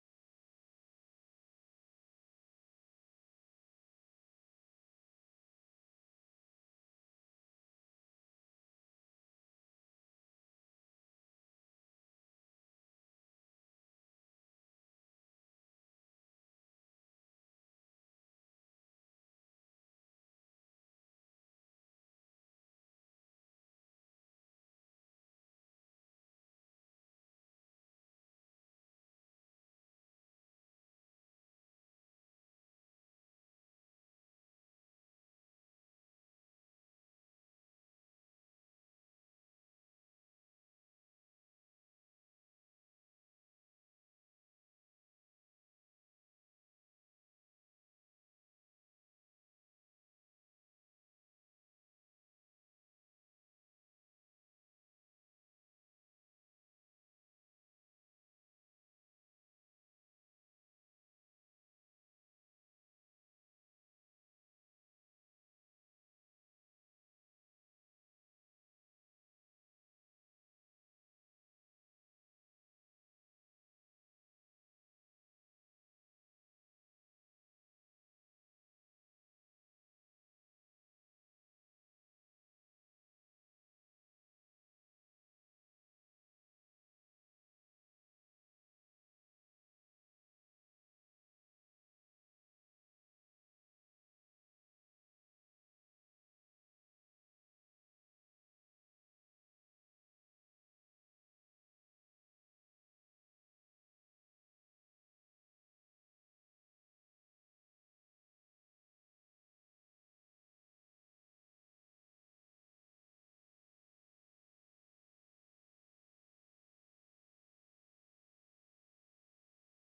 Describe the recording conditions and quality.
Our apologies we had a slight technical difficulty today.